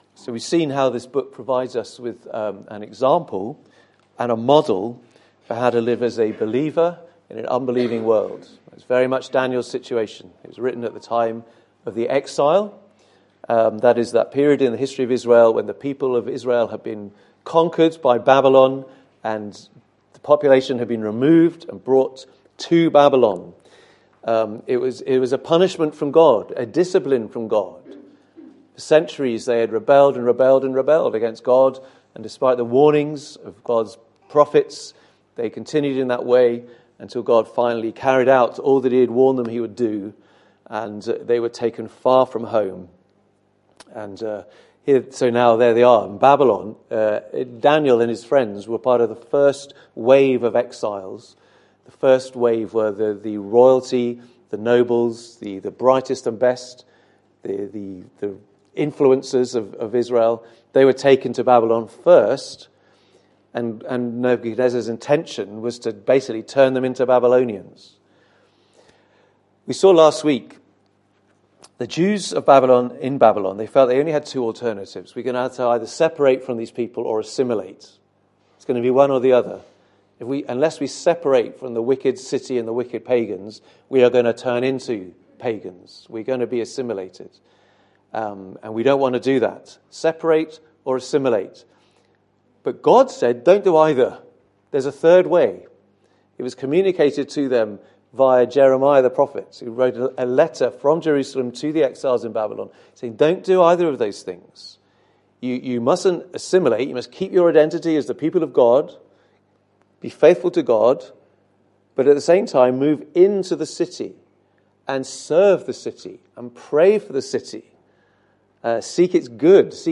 Book of Daniel Passage: Daniel 2:1-49 Service Type: Sunday Morning « A Window into a 1st C Local Church Don’t Fear